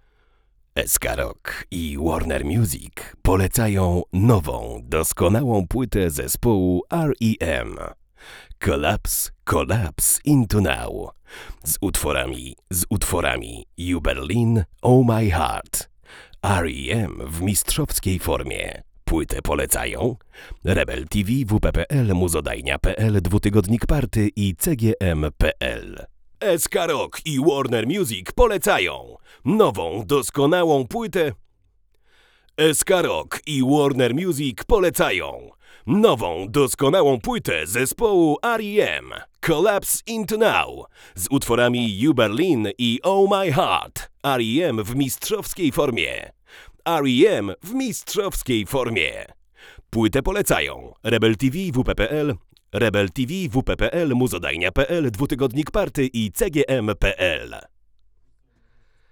Zwraca uwagę niski poziom szumów własnych, niewątpliwy plus.
Dźwięk jest mocno obcięty w niskim paśmie, podbity w średnim i ogólnie w charakterze przypomina trochę tańsze produkcje tranzystorowe.
Na dodatek, na wszystkich głosach wyszła ponadprzeciętna wrażliwość konstrukcji na głoski wybuchowe.